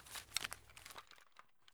hide_shotgun.ogg